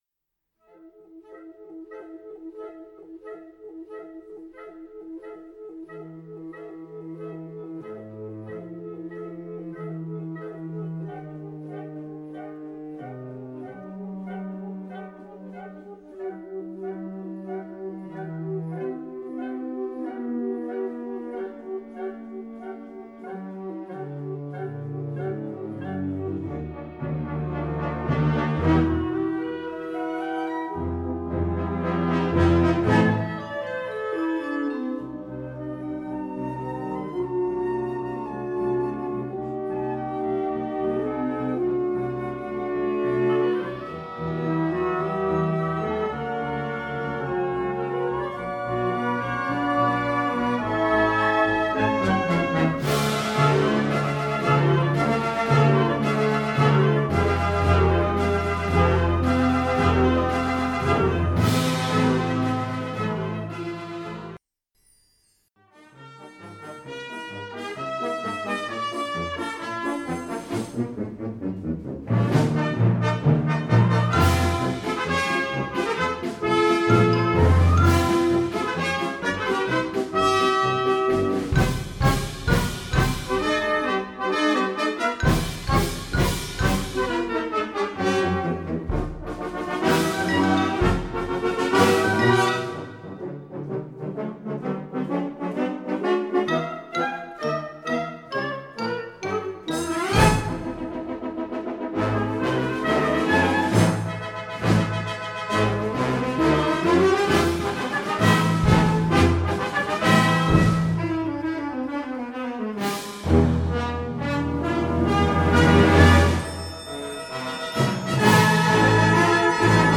Categoria Concert/wind/brass band
Sottocategoria Musica per concerti
Instrumentation Ha (orchestra di strumenti a faito)
L'atmosfera è oscura e mistica.